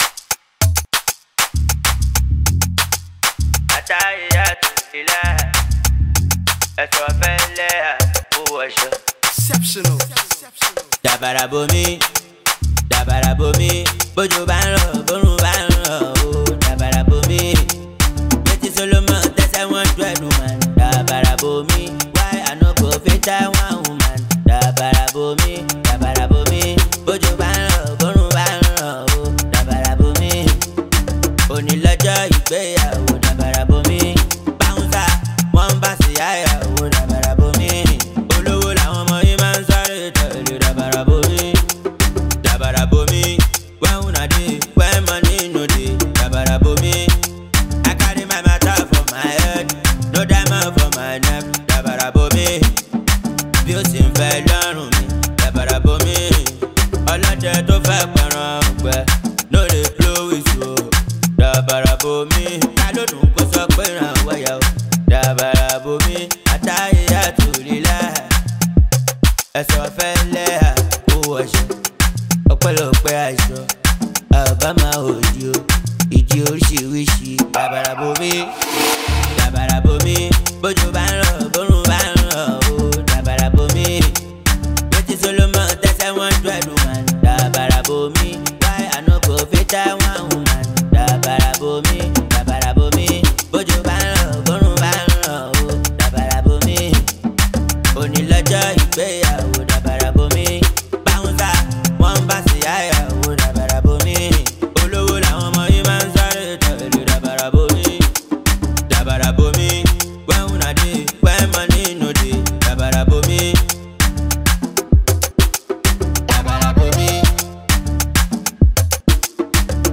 | Coupé décalé